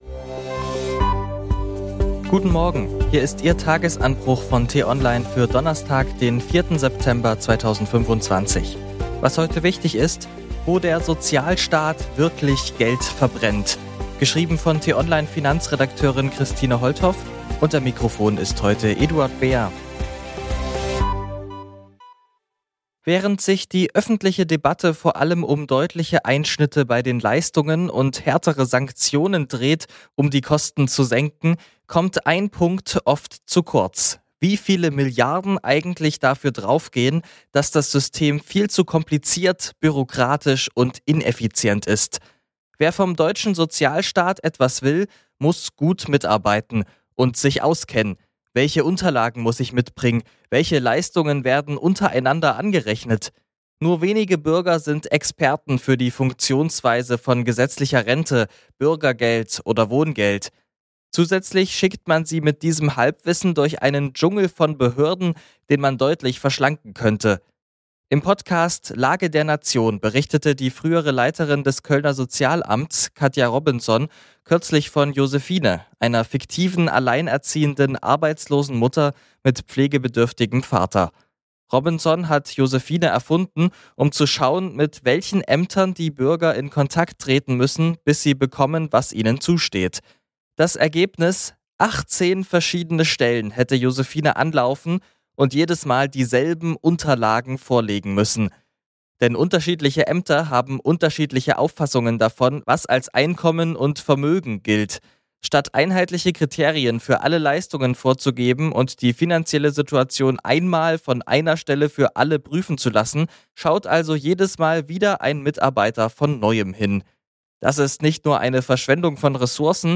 Der Nachrichtenpodcast von t-online zum Start in den Tag.